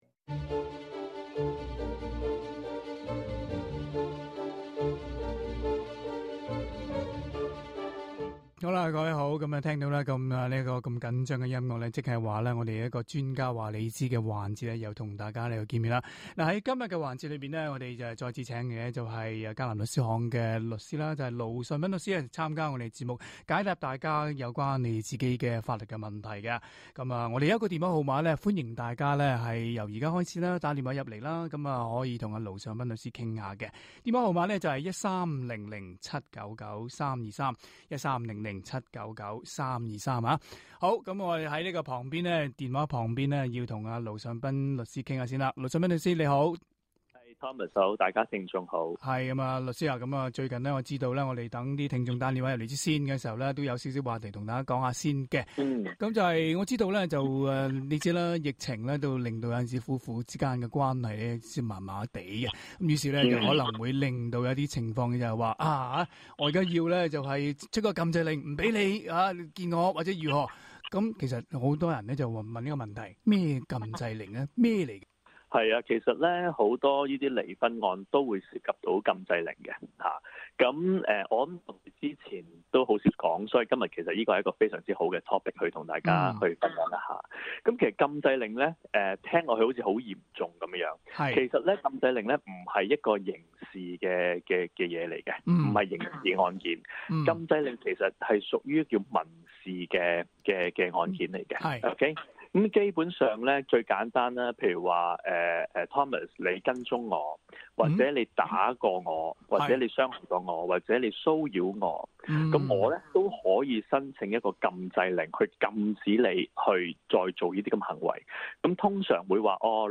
並 回答聽衆來電， 特別有關遺囑、違例泊車、告發吸毒等問題。